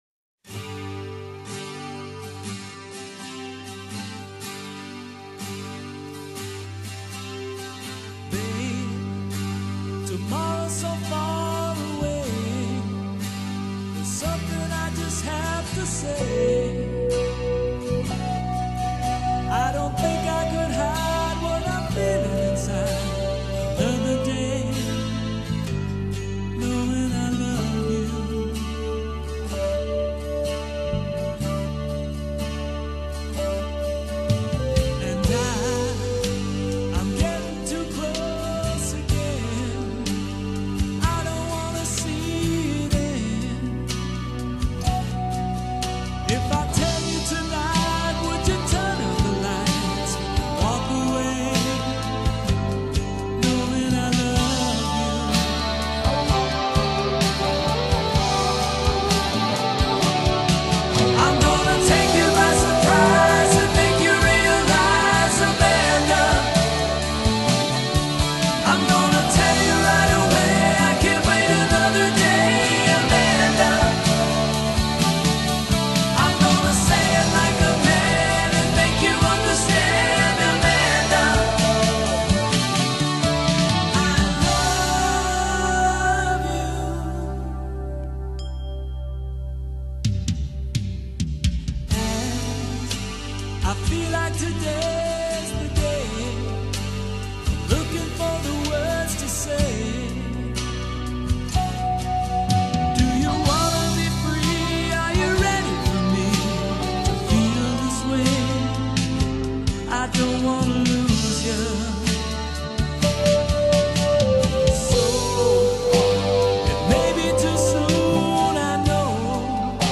強調真實的搖滾風格與搖滾不死的音樂精神外，堅持不用電子合成器
這是一首詞曲兼憂的抒情搖滾佳作。